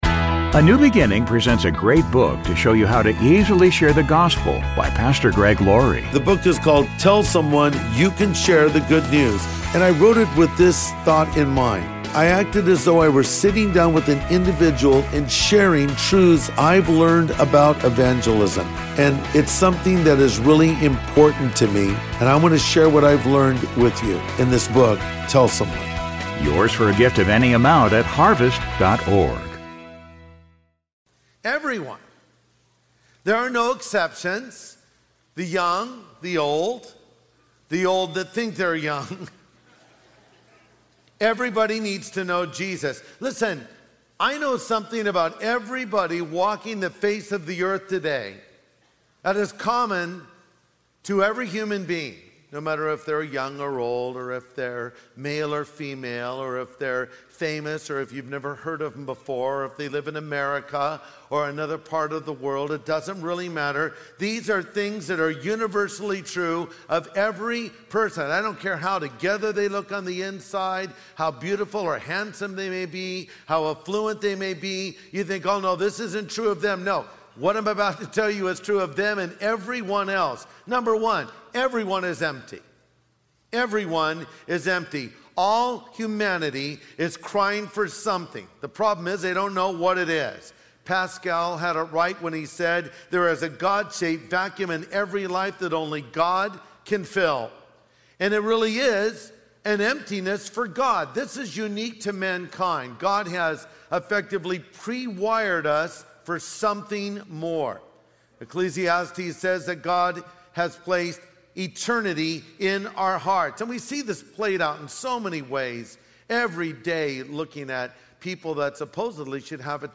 Pastor Greg Laurie shares a truthful message: Everyone needs Jesus. He explores the universal struggles of loneliness, emptiness, and guilt, offering hope through Christ.